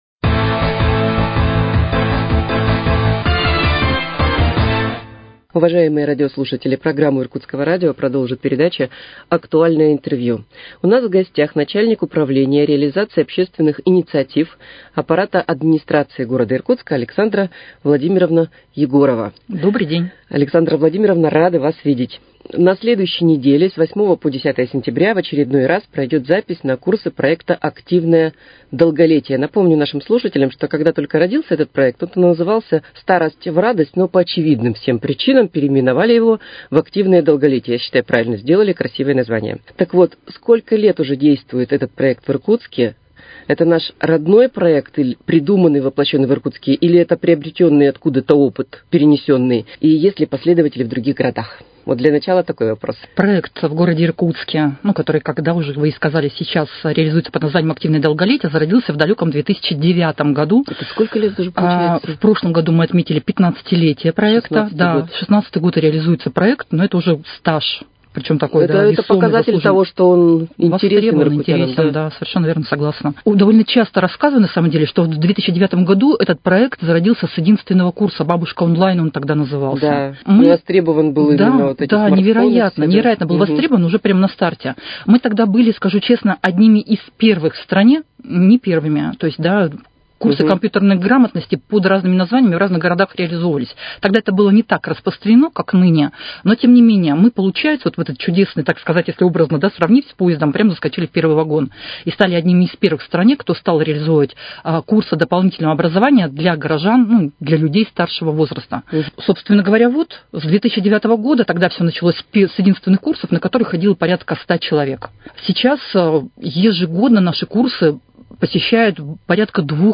Актуальное интервью: Об открытии записи на курсы проекта «Активное долголетие»